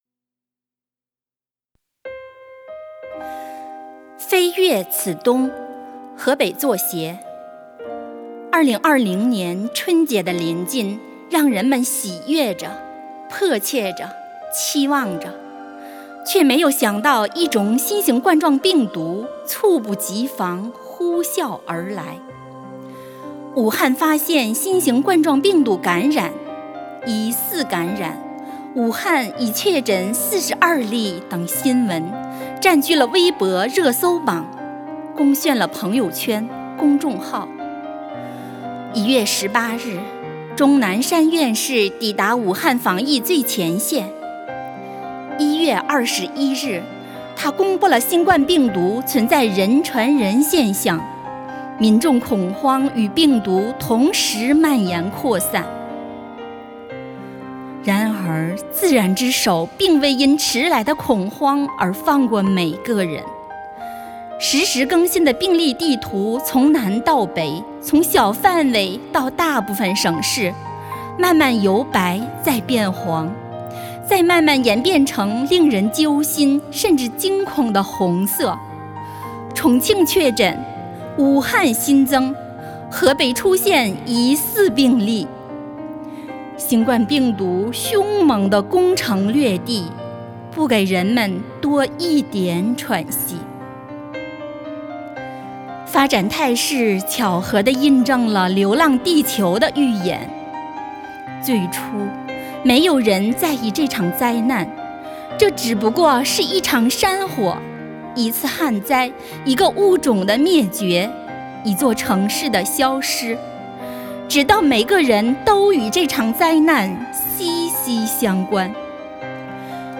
为声援战斗在一线的工作人员，鼓舞全区人民抗击疫情的信心和决心，丰南文化馆、百花艺术团、丰南诗歌与朗诵协会组织诗歌与诵读工作者、爱好者共同创作录制诵读作品。